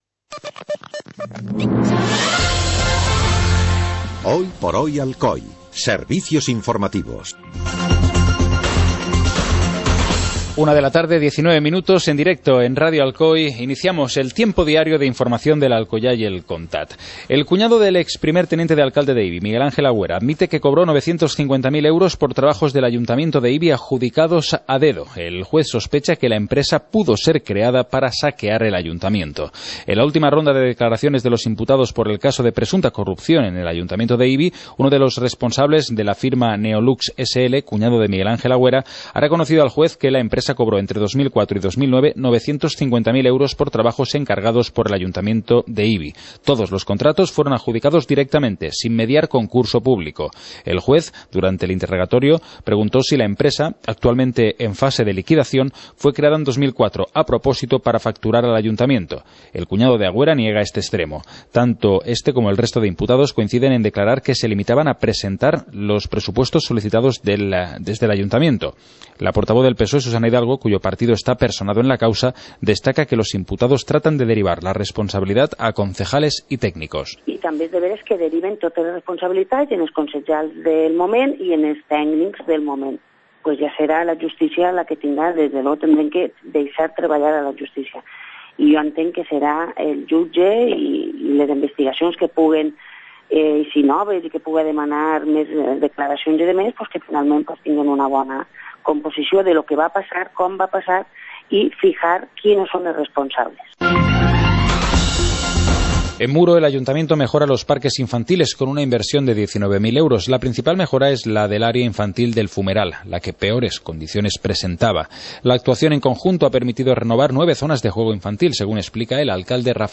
Informativo comarcal - lunes, 17 de noviembre de 2014